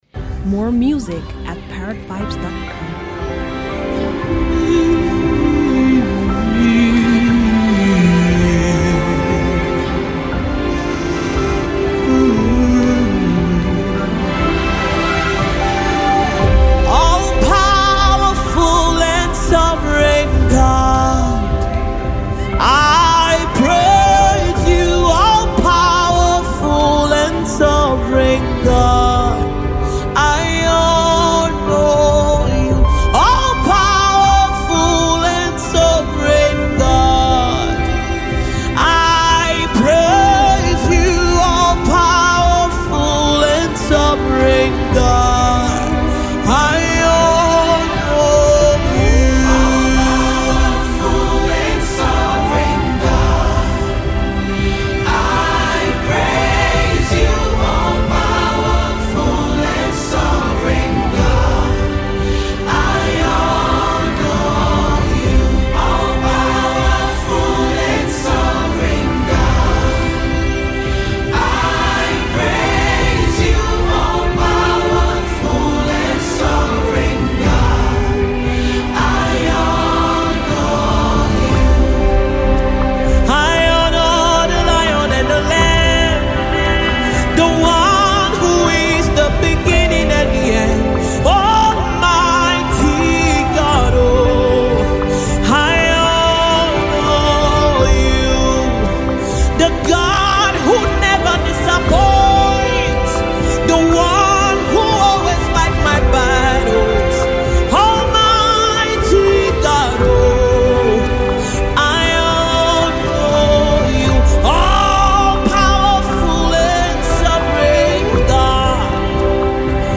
Vocally competent Nigerian gospel singer and songwriter
GOSPEL